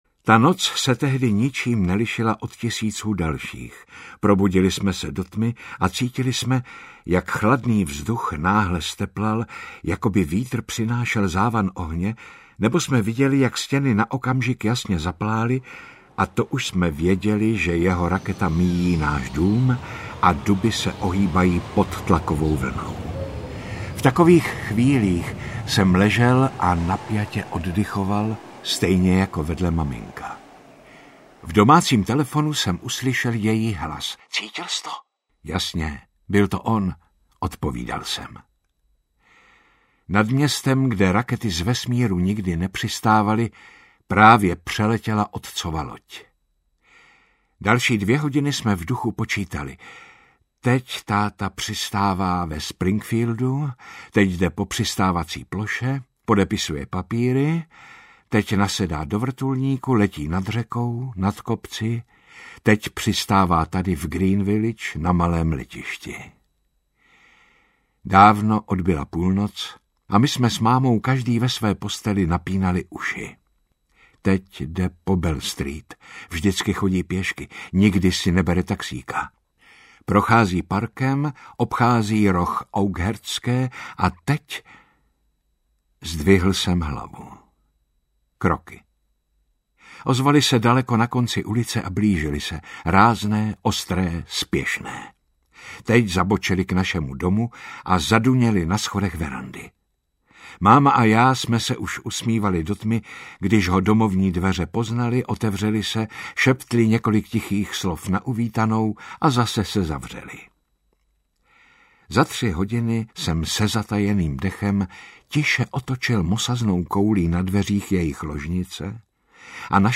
Audioknihy